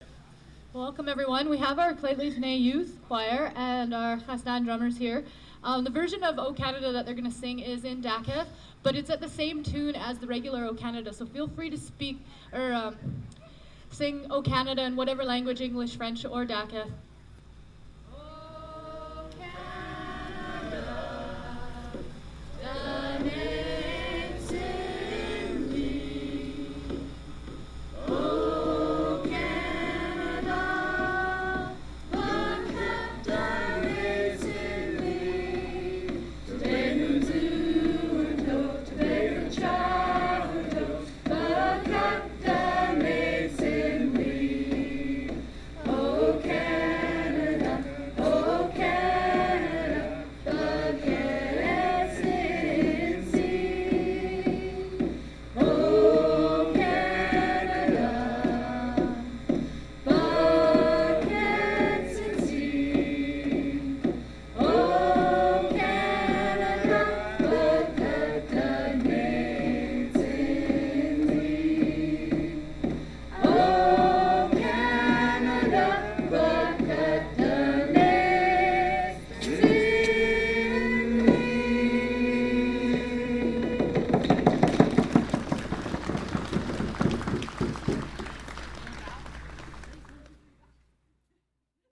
Performed by the Lheidli T'enneh Youth Choir and the Khast'an Drummers at National Aboriginal Day celebrations in Prince George.